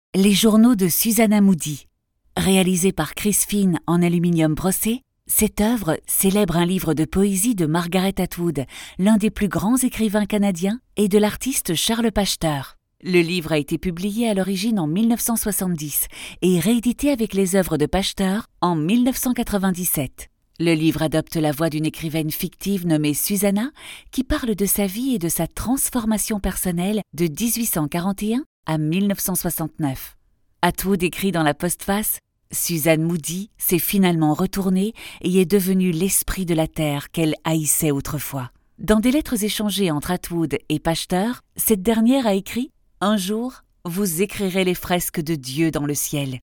Commercial, Playful, Friendly, Versatile, Soft
Audio guide